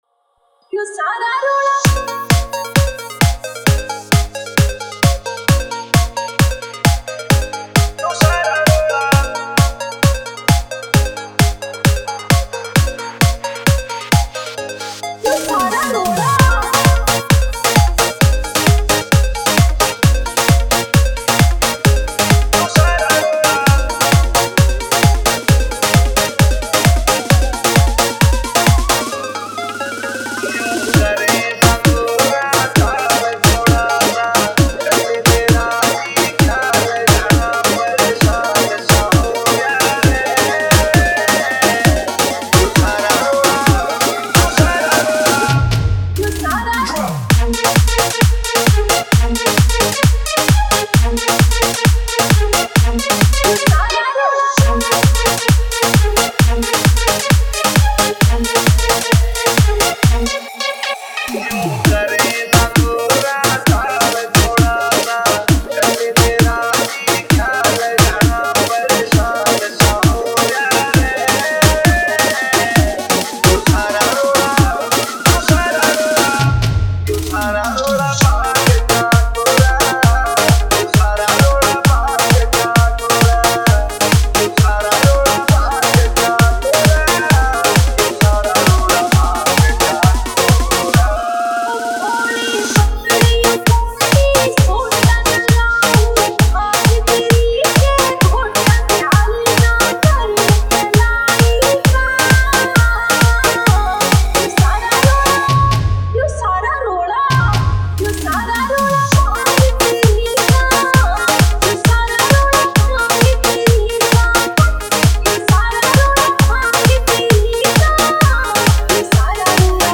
Shivratri Special DJ Remix Songs